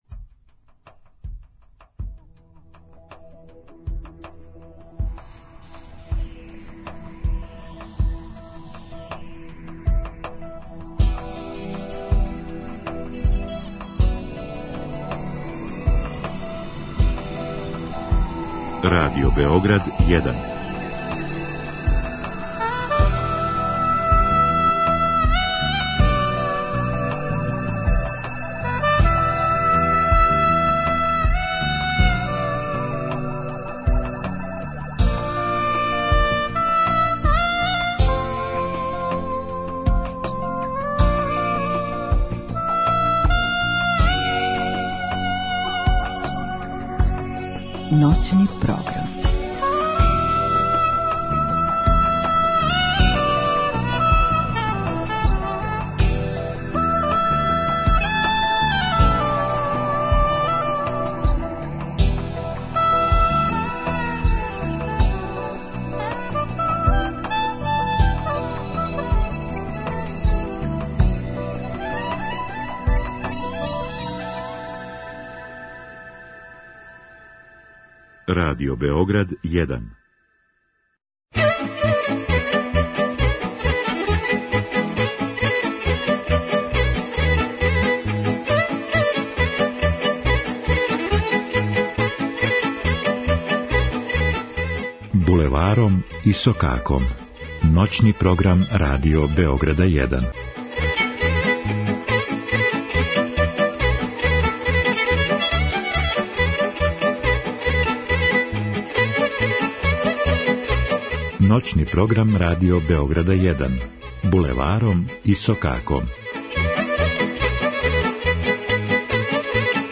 У емисији можете слушати изворну, староградску и музику у духу традиције.